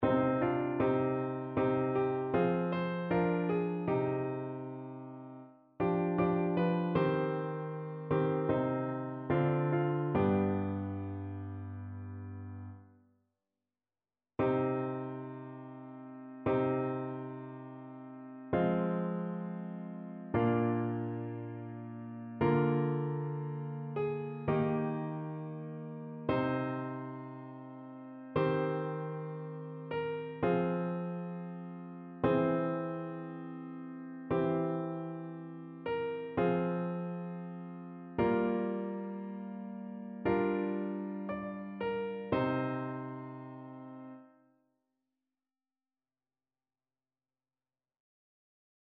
ChœurSopranoAltoTénorBasse
annee-b-temps-ordinaire-4e-dimanche-psaume-94-satb.mp3